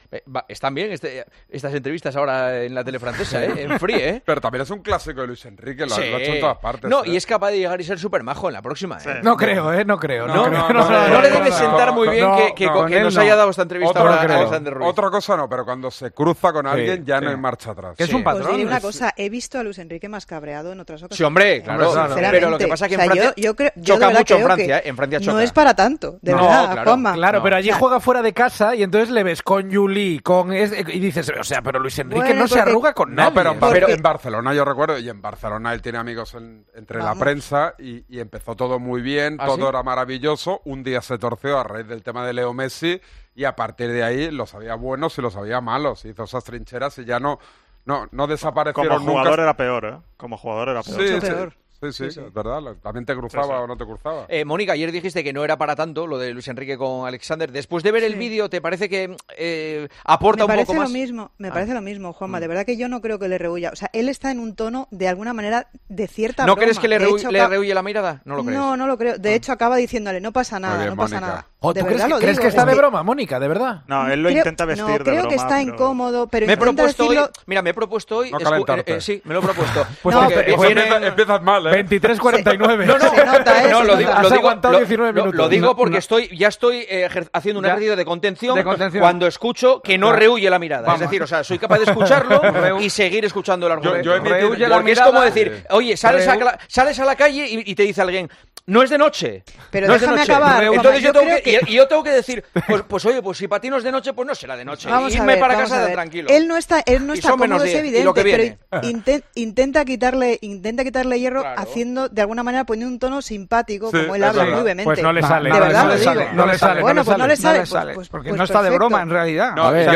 La postura de Juanma Castaño, esta vez, fue de contención: "Me lo he propuesto", comentaba en un tono desenfadado el director del programa.